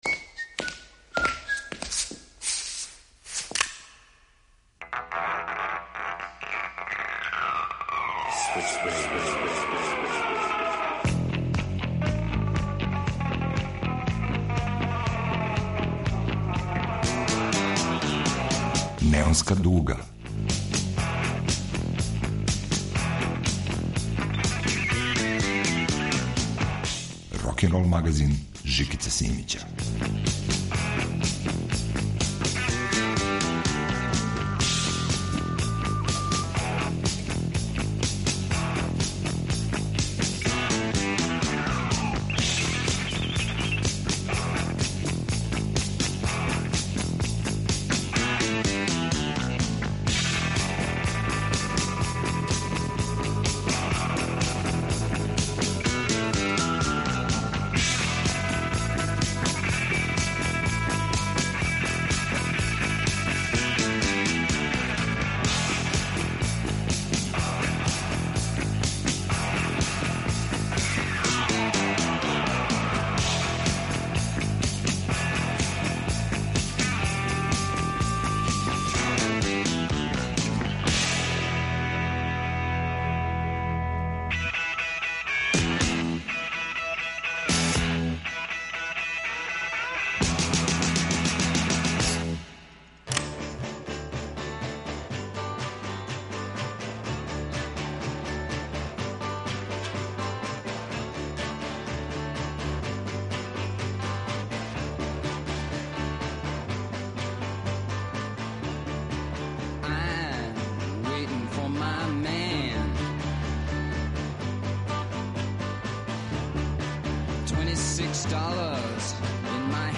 рокенрол магазин